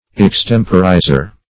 Extemporizer \Ex*tem"po*ri`zer\, n. One who extemporizes.